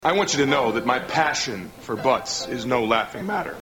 Tags: Pat Finger is Running in Butts Pat Finger Running in Butts Pat Finger funny skit snl skit